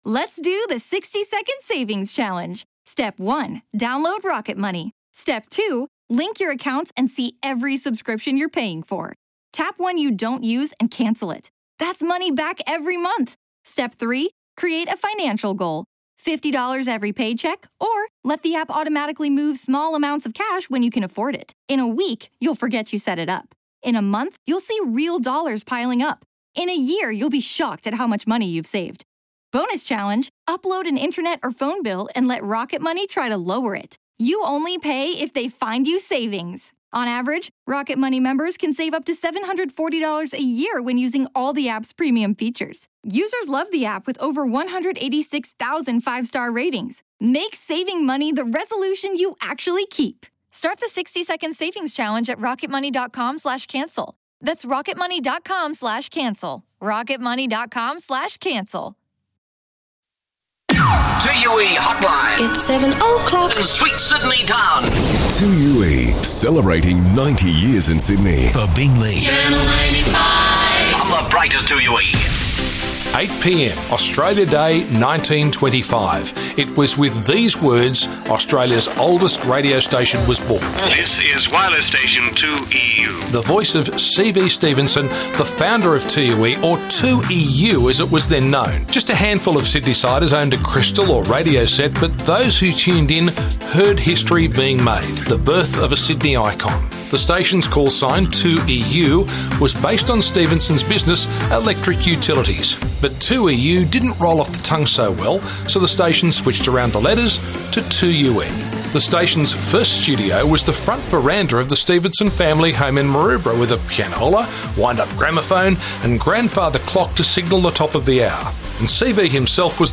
Take a listen back to some classic 2UE flashbacks on 90 years of broadcasting to Sydney and around the globe.